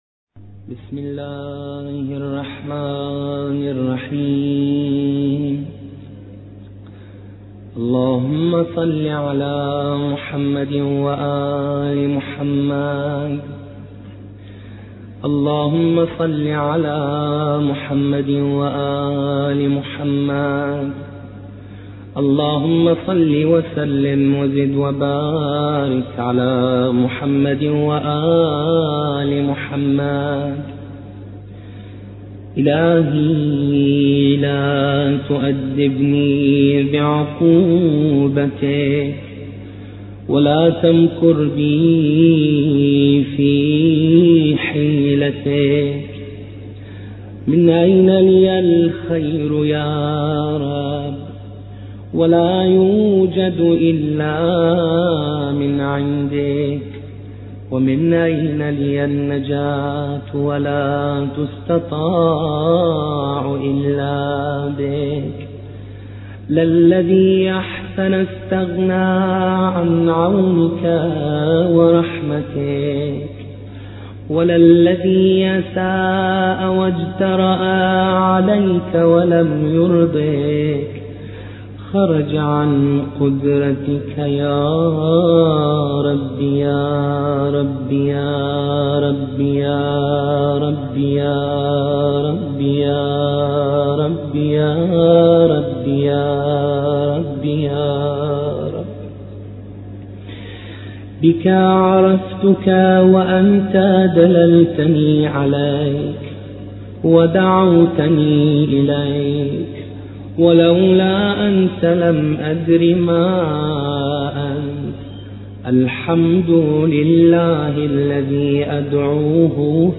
ملف صوتی دعاء ابي حمزه الثمالي بصوت الشيخ حسين الأكرف